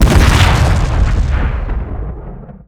plane_explosion1.wav